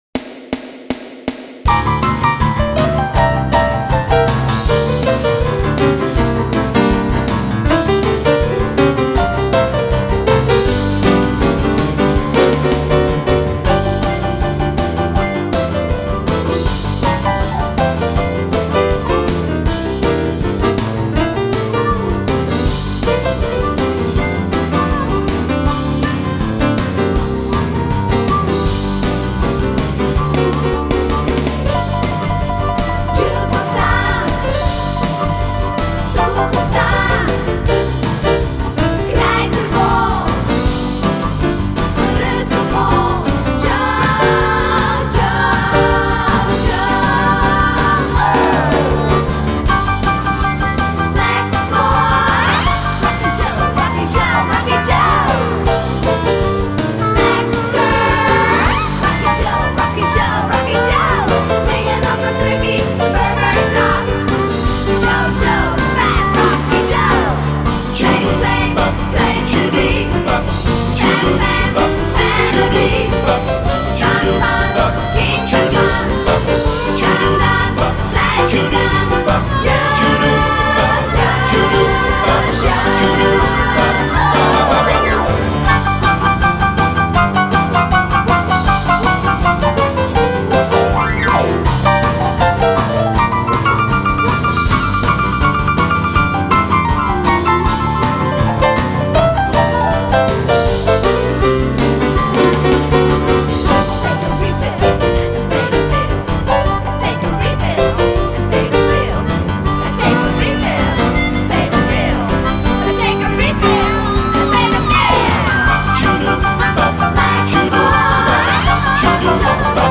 SchouderCom - Zonder zang
Lied-5-Fast-Fat-Rocky-Joe-instrumentaal-.mp3